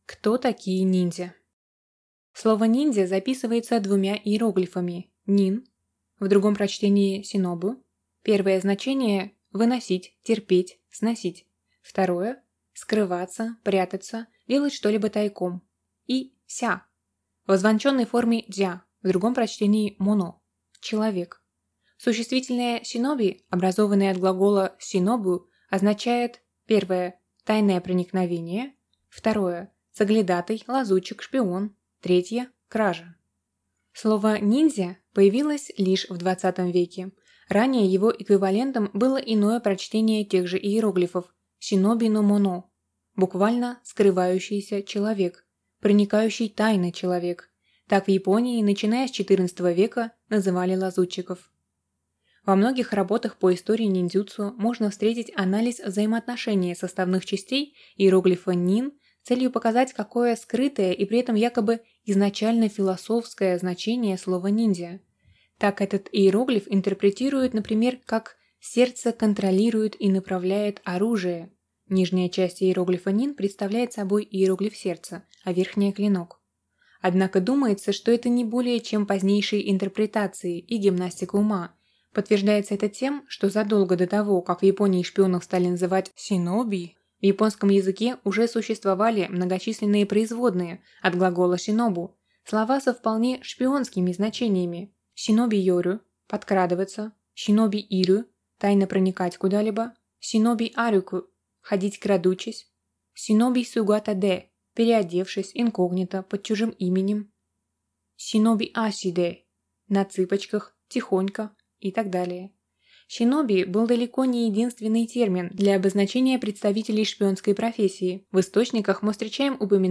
Аудиокнига Ниндзя. Первая полная энциклопедия | Библиотека аудиокниг